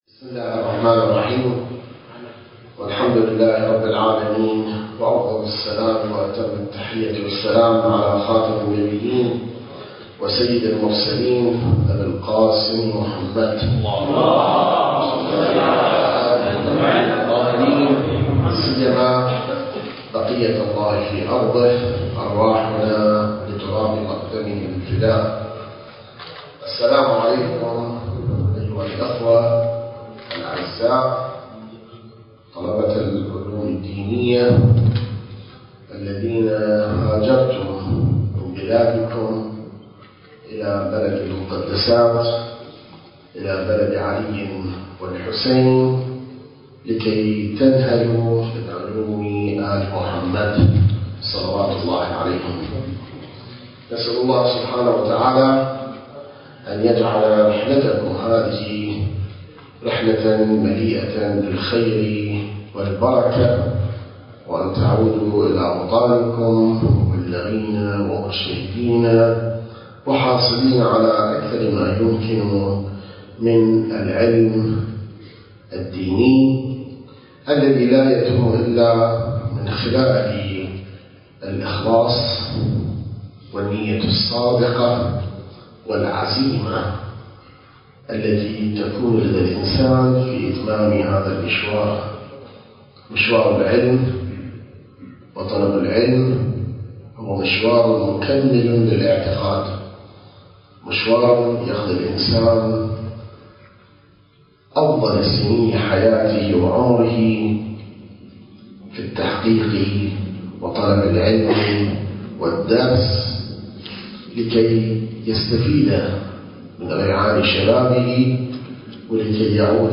المكان: مؤسسة دار الحكمة